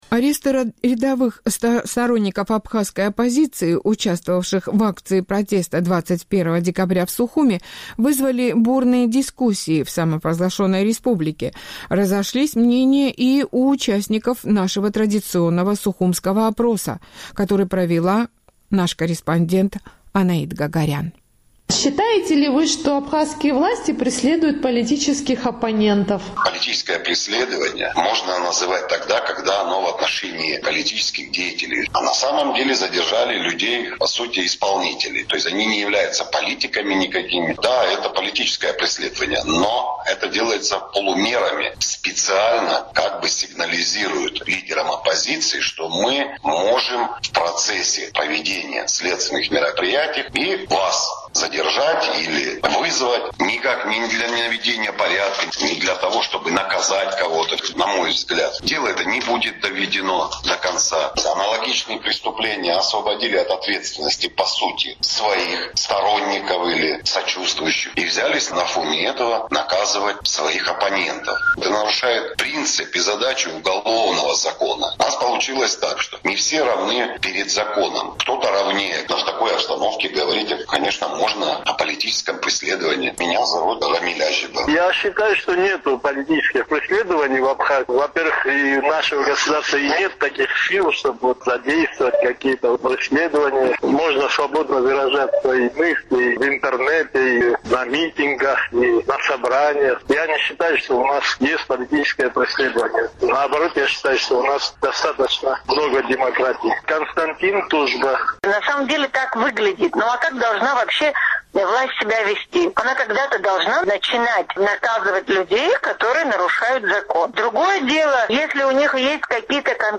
Аресты рядовых сторонников абхазской оппозиции, участвовавших в акции протеста 21 декабря, вызвали бурные дискуссии в обществе. Разошлись мнения и у участников нашего традиционного сухумского опроса.